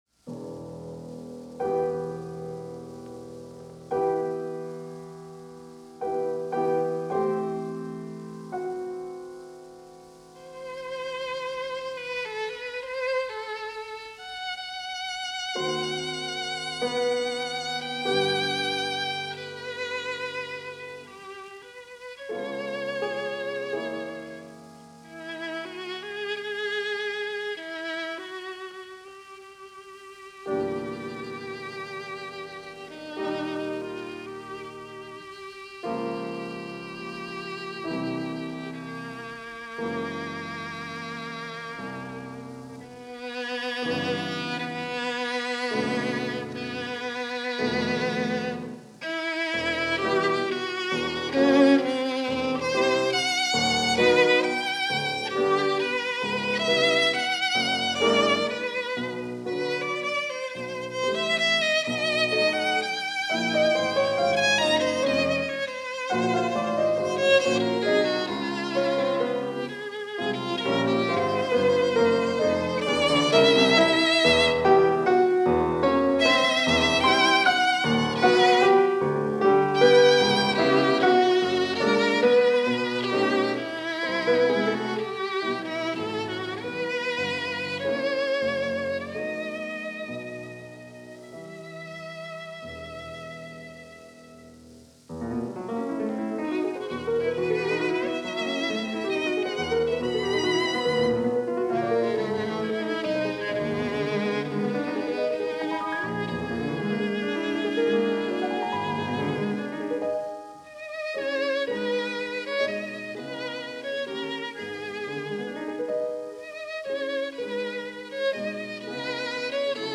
vln
pno